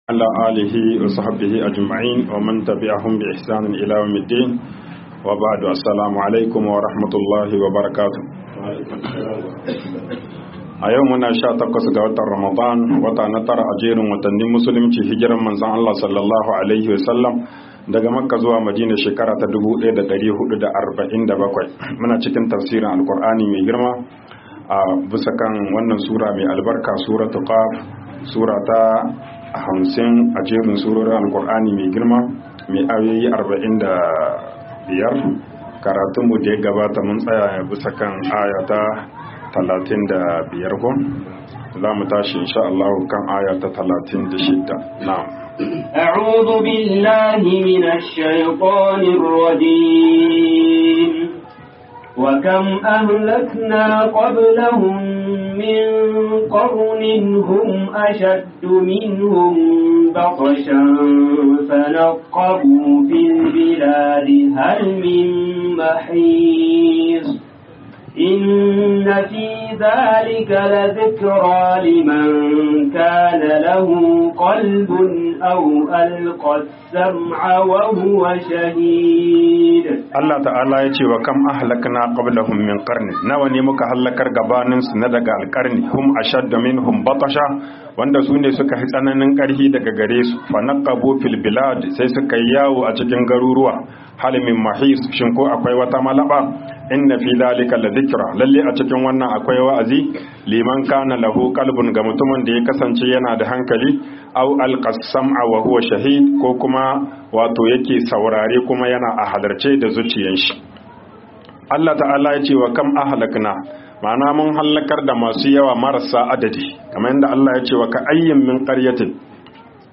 TAFSIR RAMADAN MASJID ALSHABAB 15